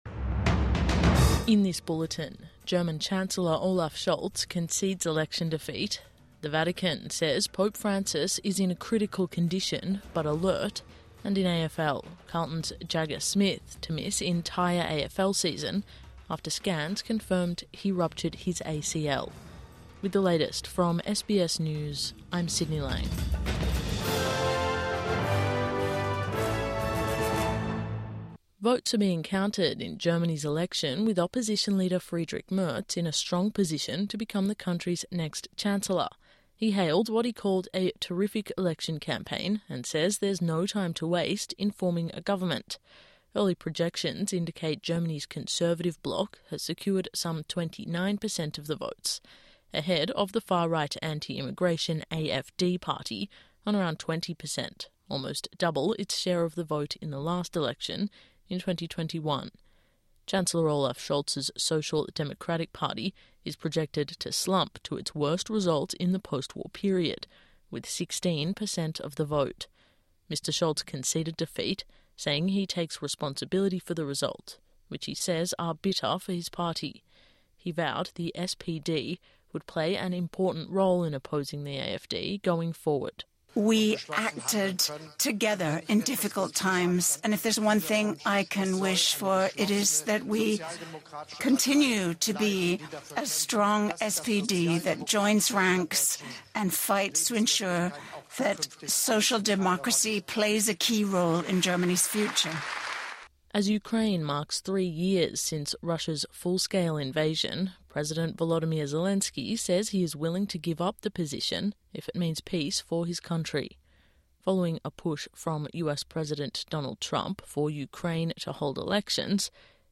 Morning News Bulletin 24 February 2025